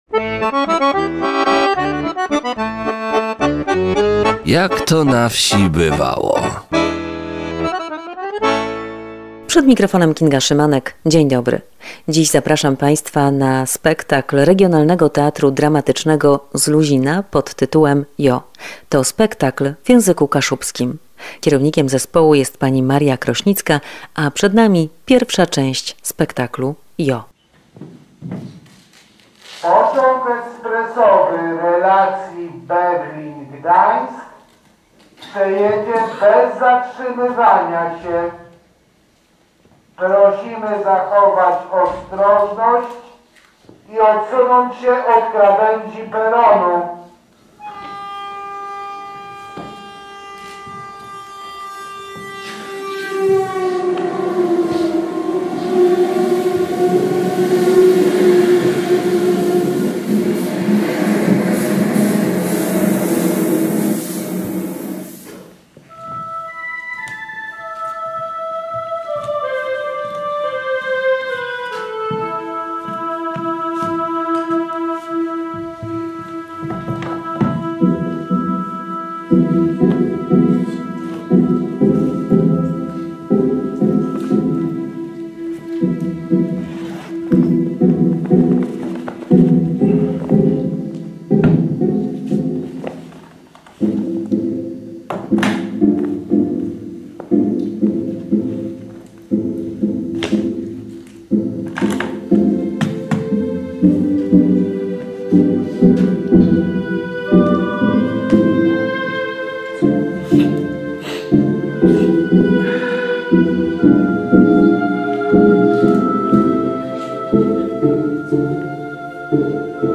Teatr Dramatyczny z Luzina zaprezentuje spektakl w języku kaszubskim „Jo!” cz. 1.